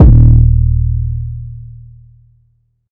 808s
BASS_DST612.wav